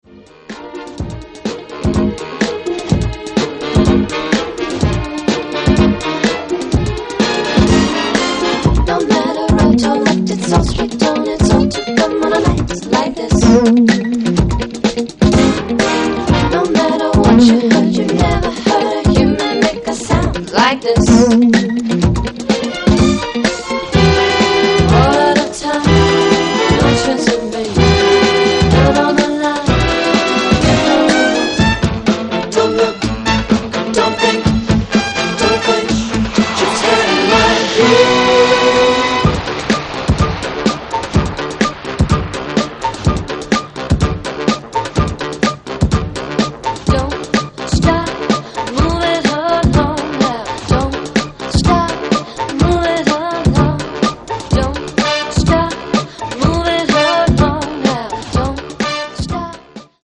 lush sounds, rhythms and great vocals